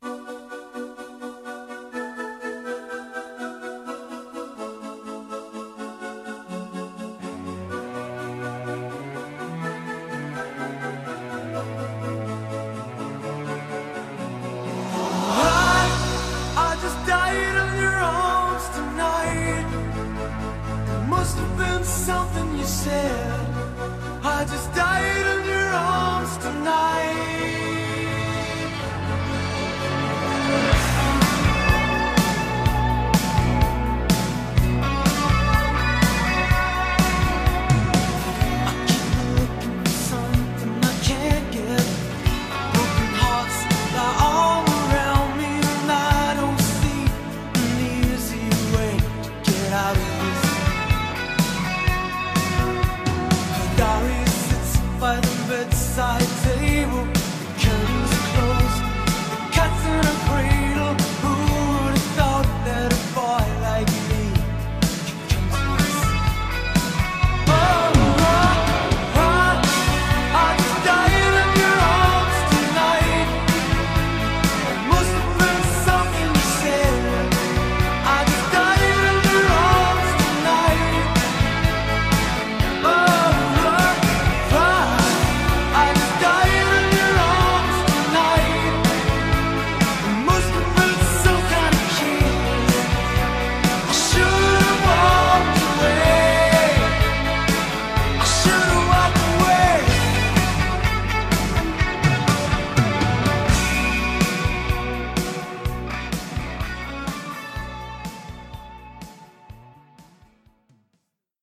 BPM125
Audio QualityMusic Cut